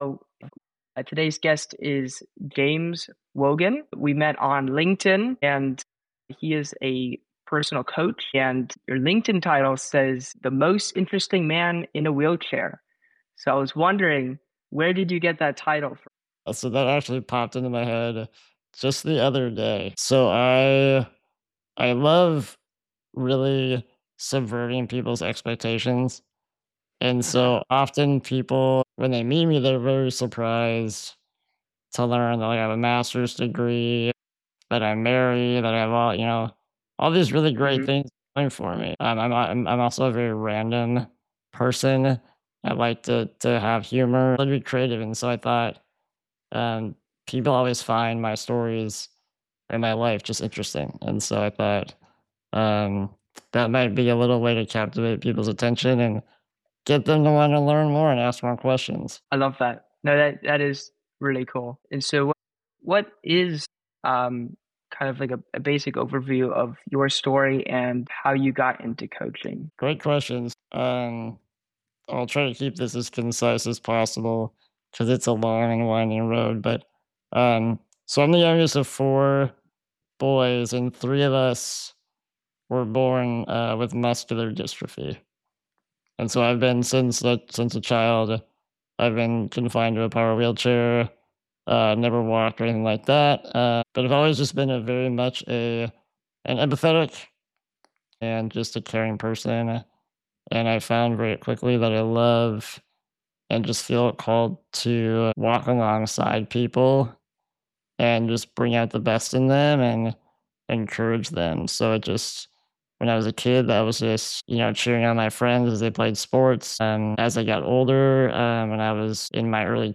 Christian Ambition is a podcast dedicated to honest conversations with Christian founders, executives, creators, and thought leaders who are building great things without compromising their faith.